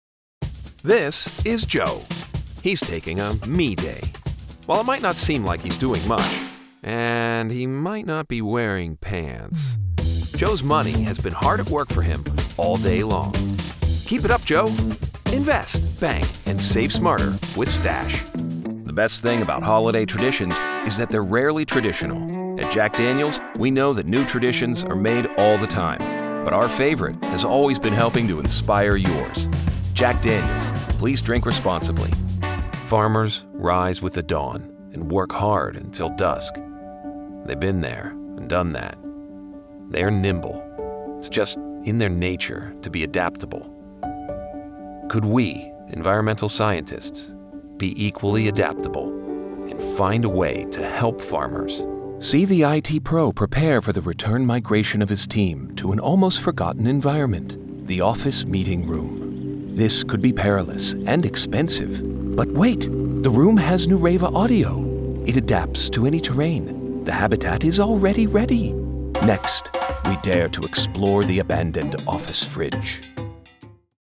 Professional American male voice. Conversational, guy next door, instructional, strong and occasionally snarky!
Southern, midwestern
Middle Aged